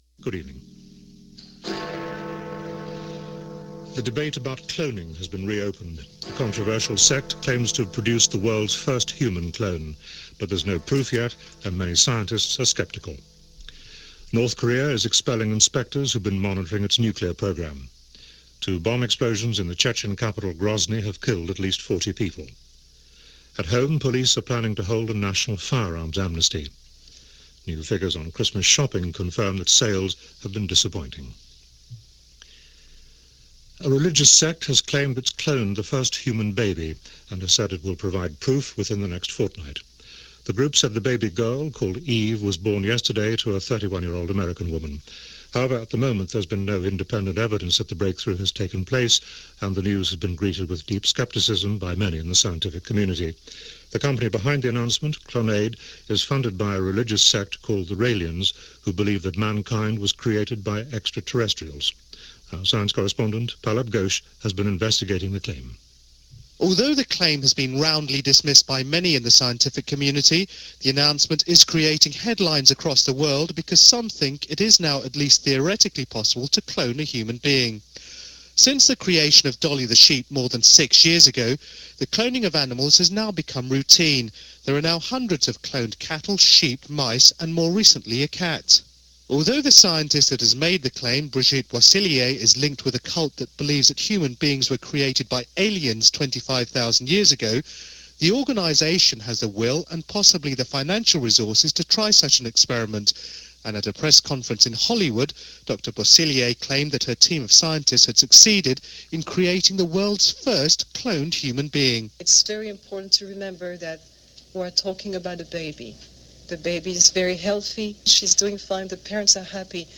BBC Radio 4 News – BBC World Service World Today